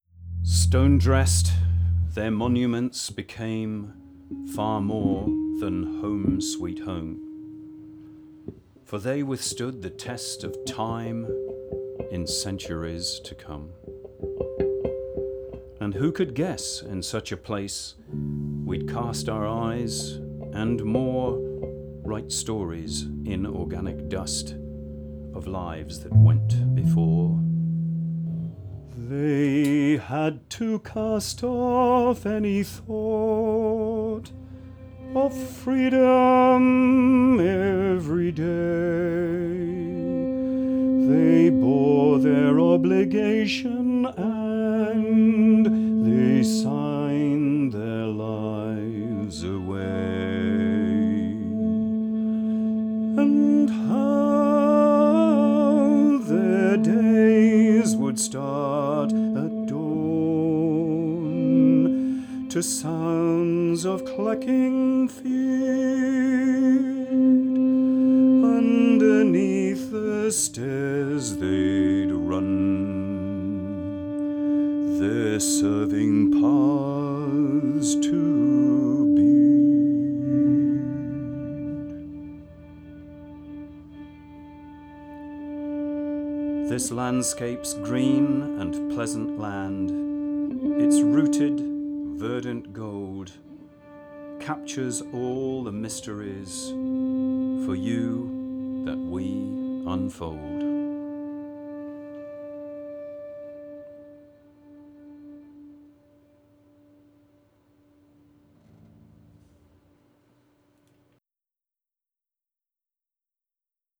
played with pick and bow on six-string bass guitar
Voice: spoken and sung
This song was comprised of six cycles of four verses; each cycle consisting of two spoken and two sung verses, the last of which was a reiterated refrain.